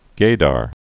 (gādär)